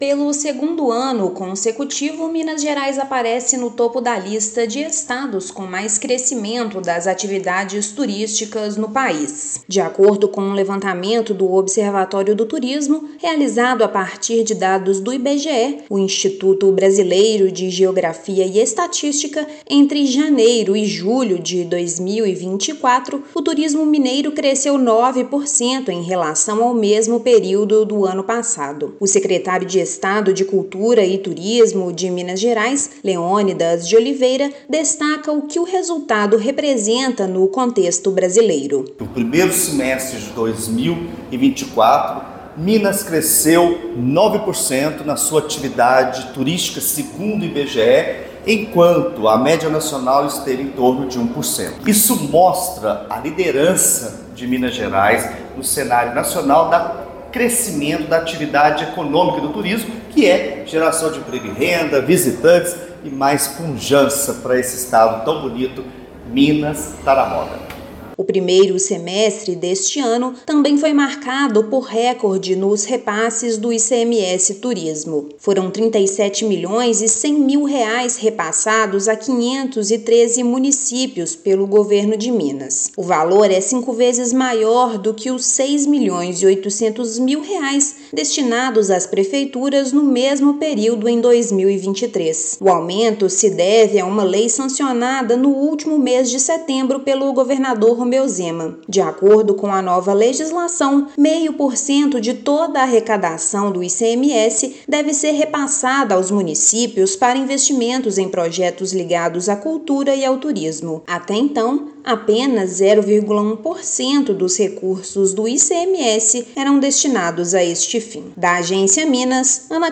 Estado teve alta de 9% em relação ao mesmo período do ano passado, e valor recorde destinado pelo Governo de Minas é cinco vezes maior que o montante efetivado de janeiro a junho de 2023. Ouça matéria de rádio.